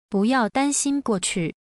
1. 過去 – guòqù – quá khứ